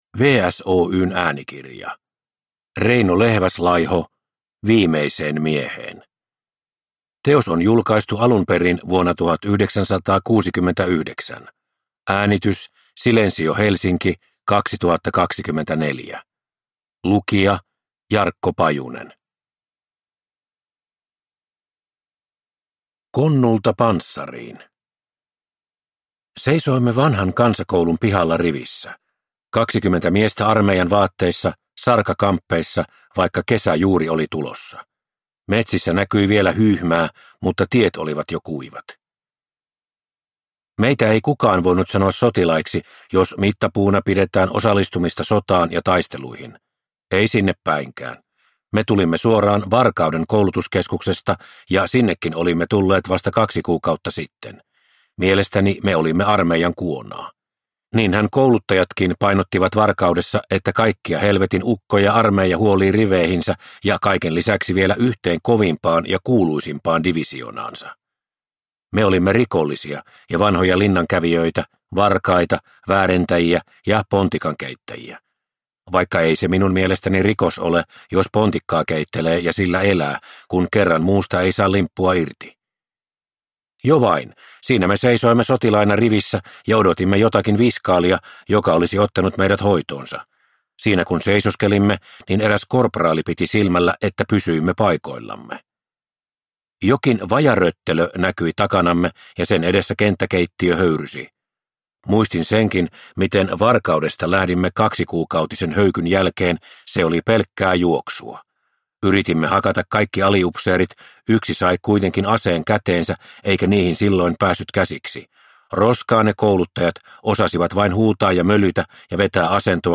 Viimeiseen mieheen – Ljudbok